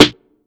• Full Snare One Shot D Key 59.wav
Royality free snare one shot tuned to the D note. Loudest frequency: 1895Hz
full-snare-one-shot-d-key-59-orj.wav